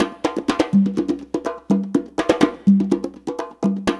CONGA BEAT45.wav